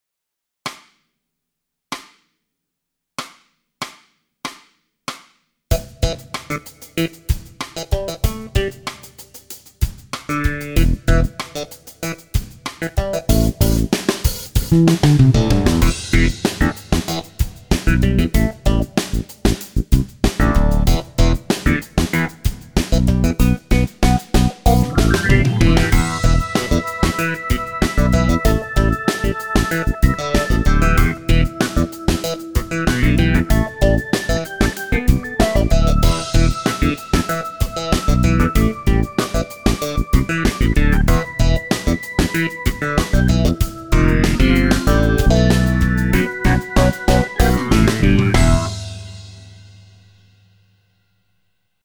Der Musikstyle „Funk“ ist durch seine rhythmische und percussive Spielweise bestens dazu geeignet, sein eigenes Rhythmusgefühl und die Koordination beider Hände zu verbessern.
Funky-Piano-Playback.mp3